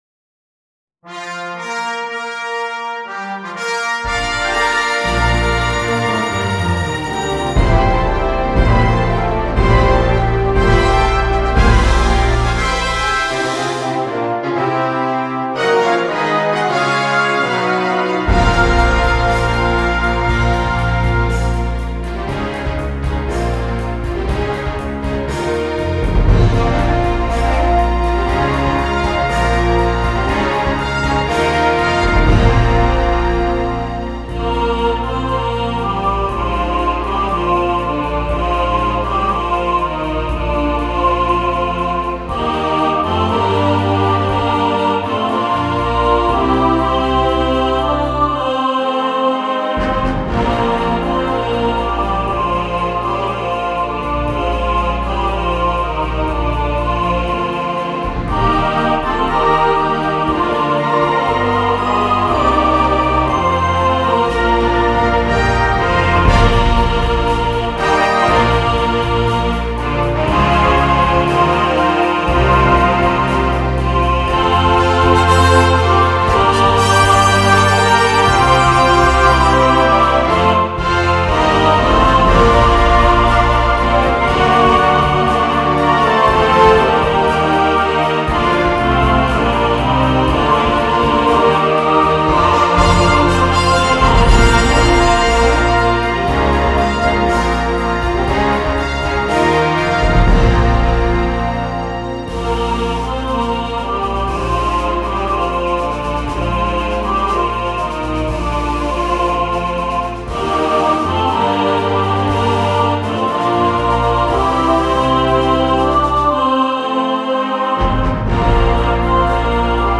for choir and orchestra.